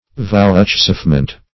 Search Result for " vouchsafement" : The Collaborative International Dictionary of English v.0.48: Vouchsafement \Vouch*safe"ment\, n. The act of vouchsafing, or that which is vouchsafed; a gift or grant in condescension.
vouchsafement.mp3